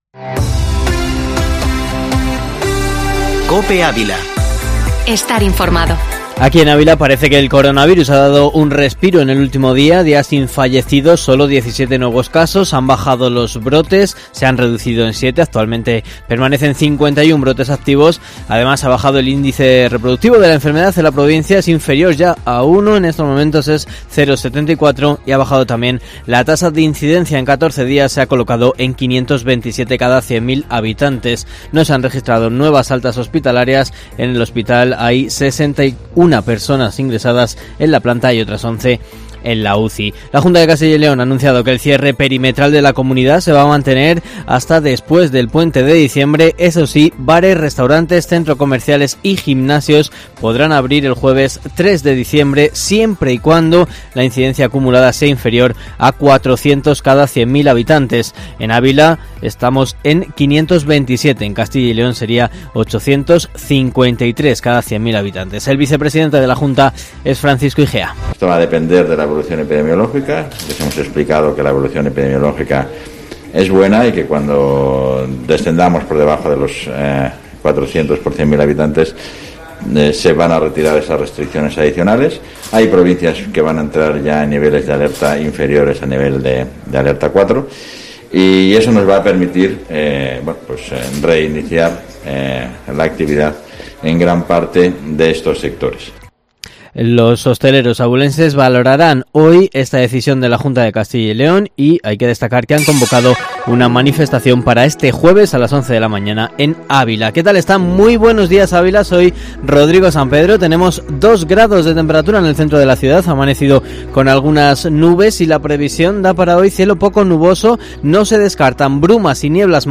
Informativo matinal Herrera en COPE Ávila 24/11/2020